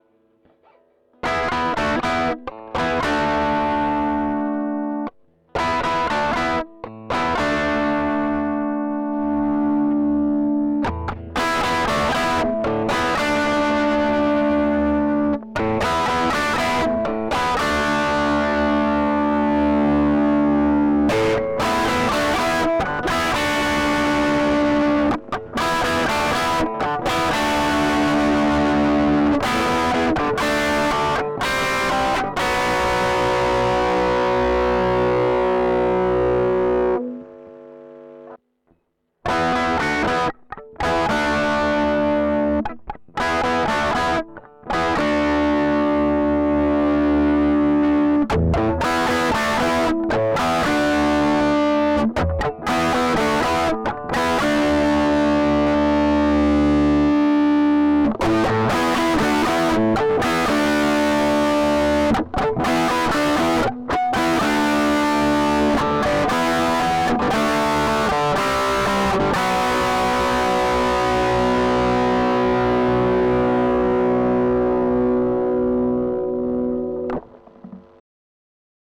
US Dream ad ore 9 - 12  - 15 diretto nella Scarlett 2i4.
La prima parte è reg. con Single coil al ponte la seconda con Humbucker al ponte.
Chitarra, US, Scarlett, Reaper.